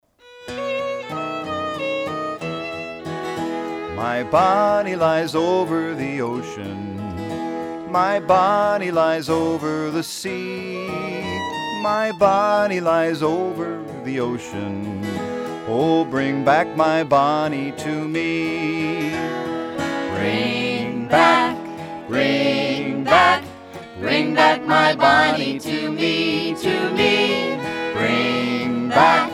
Home > Folk Songs
spirited versions of old and new folk songs